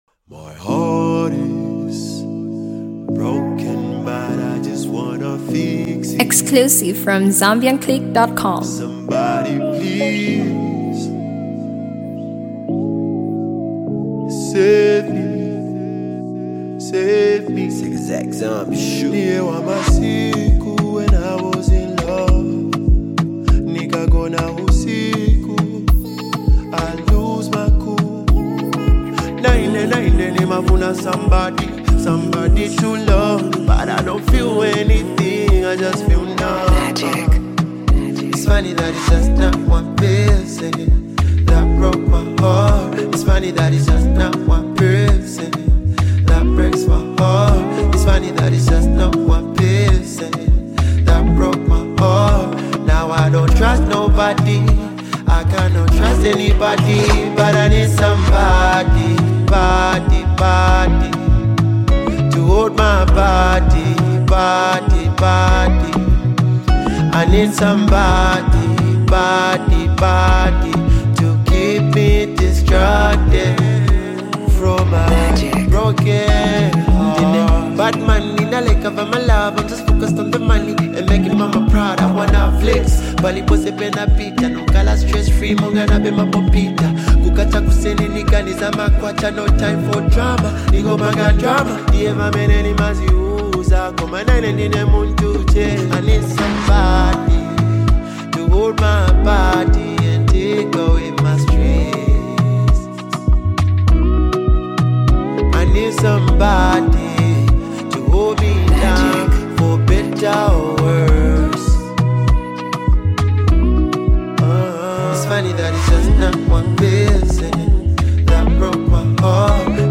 Zambian recording artist
is a love melody